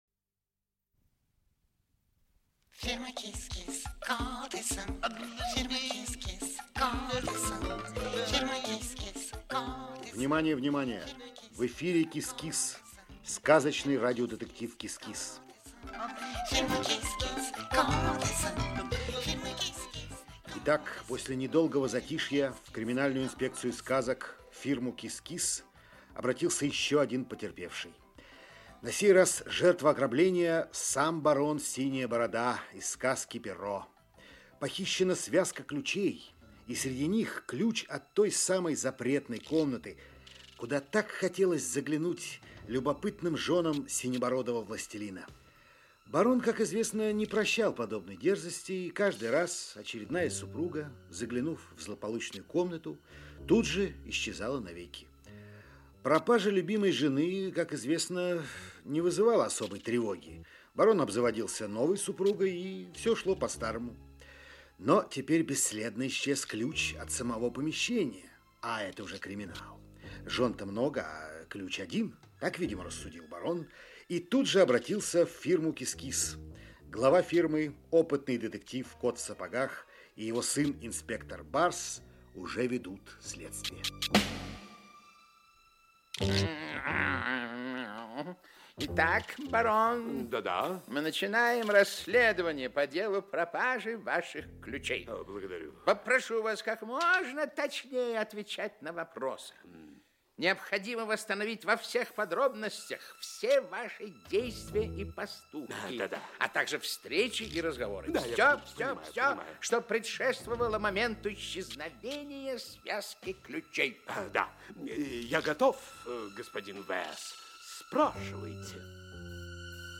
Аудиокнига КИС-КИС. Дело № 6. "Тайна Черного Замка". Часть 2 | Библиотека аудиокниг